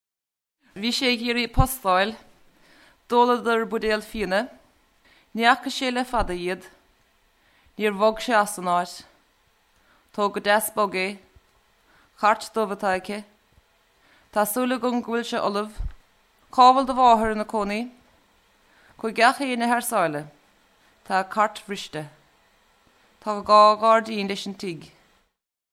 Sample sound files for Modern Irish
Each sample here is from the beginning of a recording in which the particular speaker read out a set of sentences containing tokens of the lexical sets devised for the phonology of modern Irish.
Baile_an_Sceilge_(F_35)_S.wav